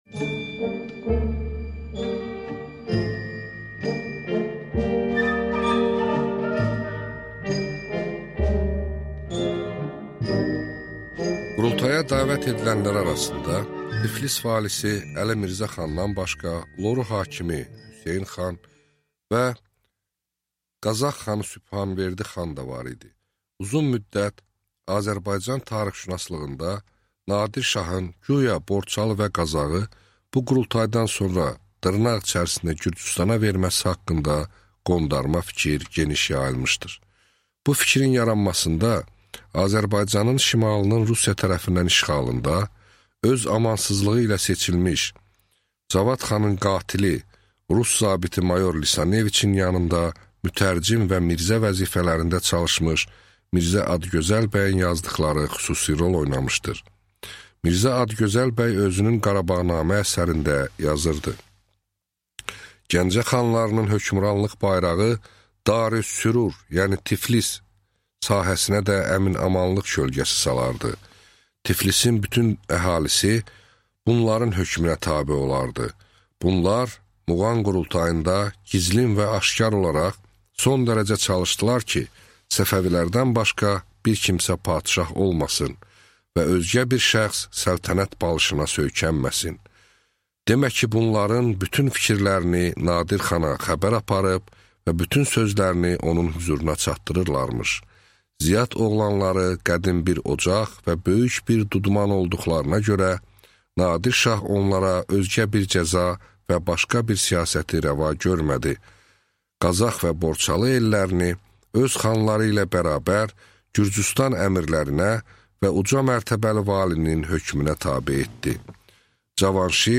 Аудиокнига XIX əsr Azərbaycan tarixi | Библиотека аудиокниг